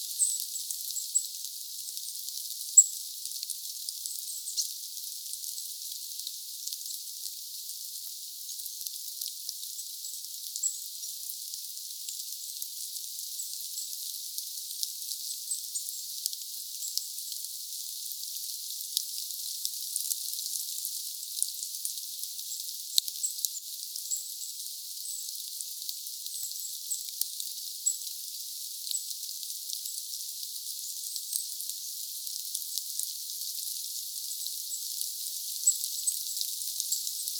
hömötiaisten vähän hippiäismäisiä ääniä?
arvaisin_naita_homotiaisiksi.mp3